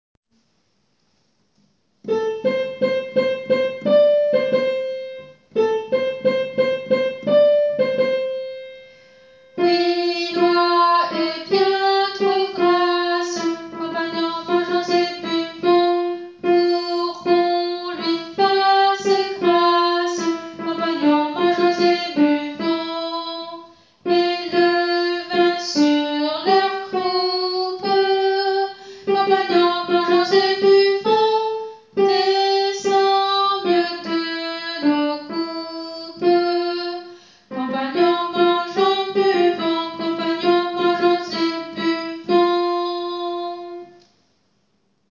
Alto :
Loie-alto.wav